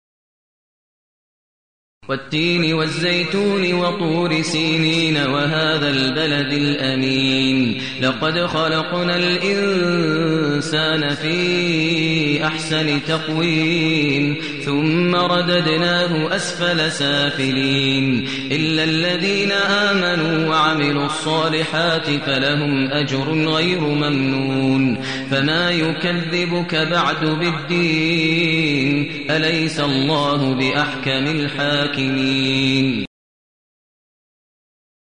المكان: المسجد النبوي الشيخ: فضيلة الشيخ ماهر المعيقلي فضيلة الشيخ ماهر المعيقلي التين